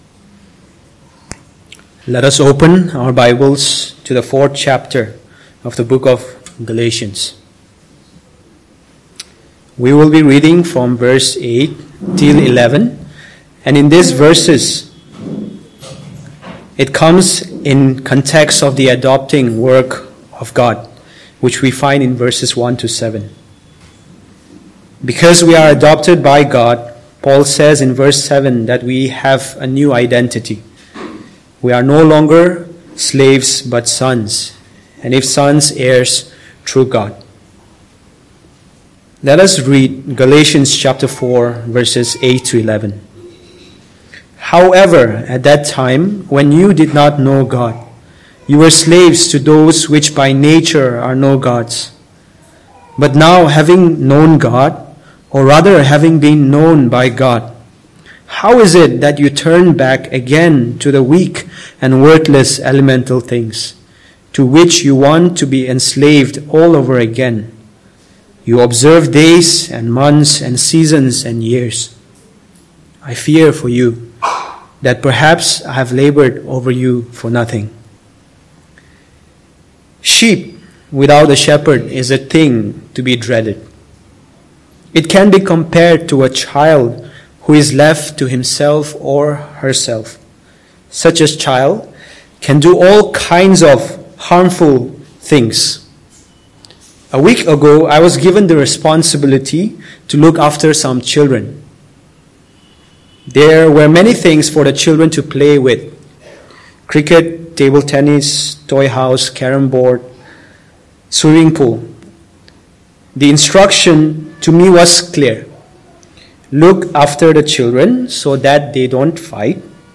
Service Type: Sunday Morning
1st-Feb-2026-Sermon.mp3